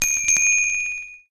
Samsung Galaxy Bildirim Sesleri - Dijital Eşik
Chime
chime.mp3